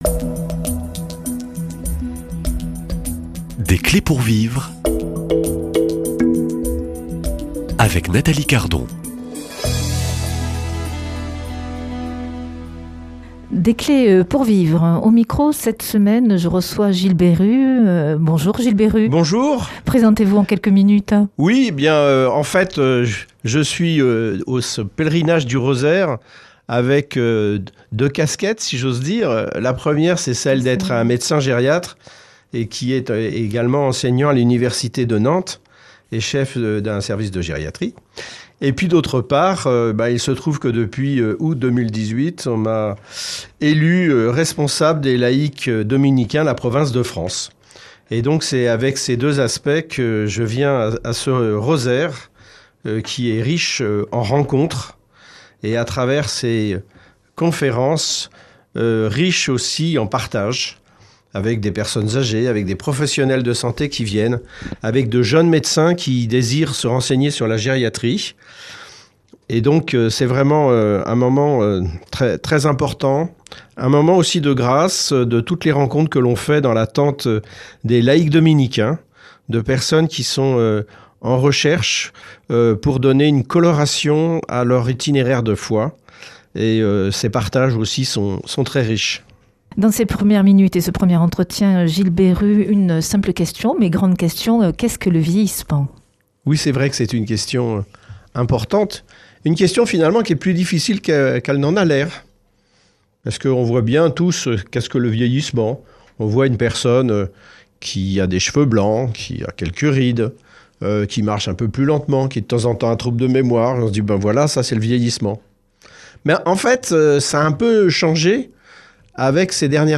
Invité